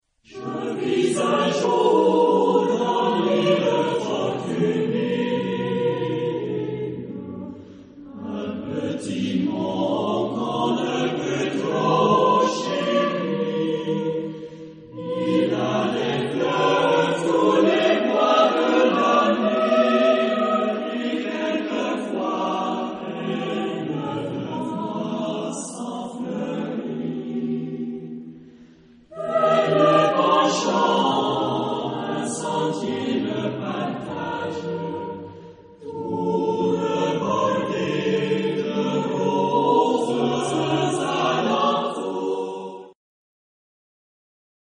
Genre-Style-Forme : Profane ; contemporain ; Chanson
Type de choeur : SATB  (4 voix mixtes )
Tonalité : mi majeur